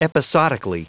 Vous êtes ici : Cours d'anglais > Outils | Audio/Vidéo > Lire un mot à haute voix > Lire le mot episodically
Prononciation du mot : episodically